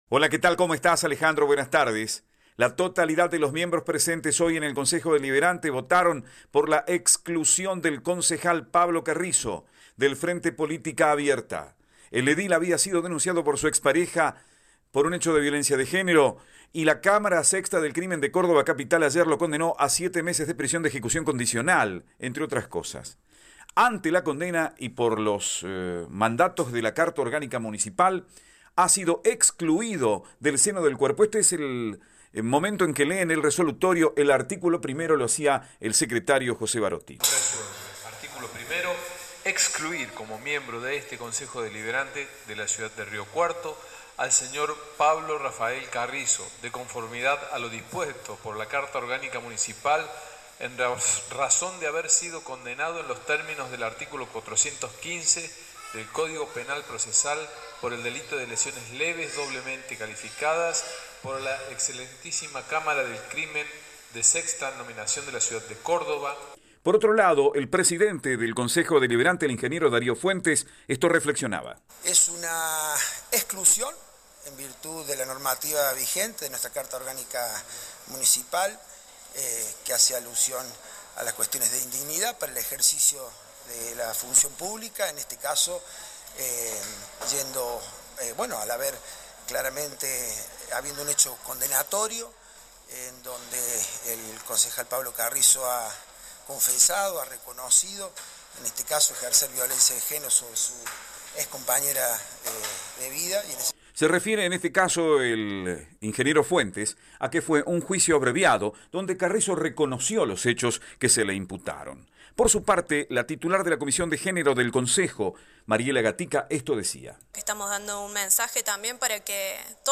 El presidente del Concejo Deliberante, Darío Fuentes, dijo a Cadena 3 que es una exclusión en virtud de la normativa vigente.
Informe